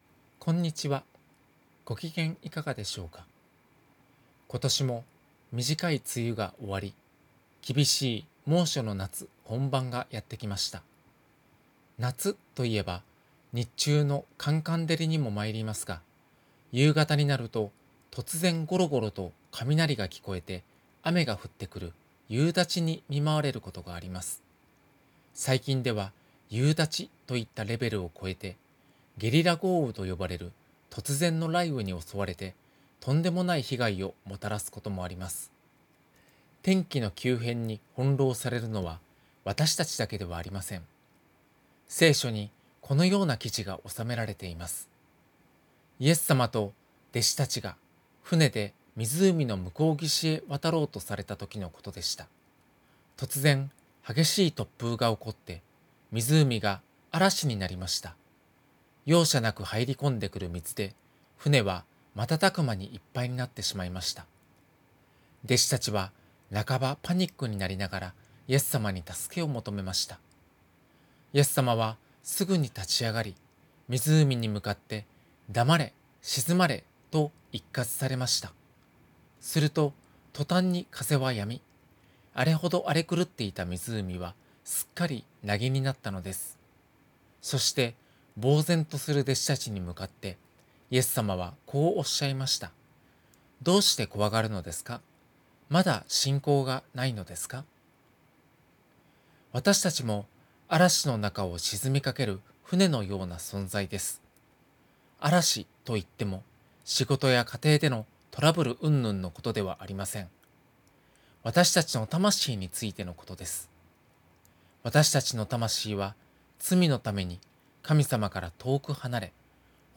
電話で約３分間のテレフォンメッセージを聞くことができます。